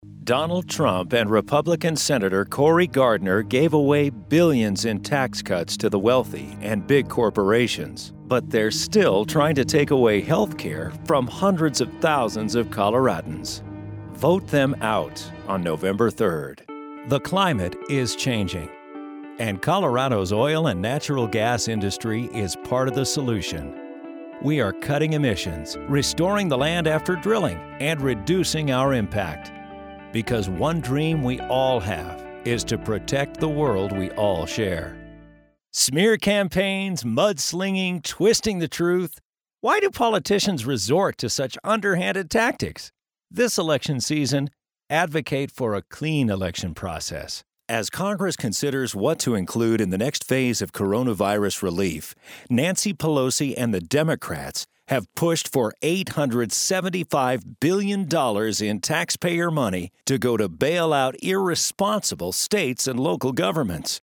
Full time American Voiceover actor and fictional character
Political
English - USA and Canada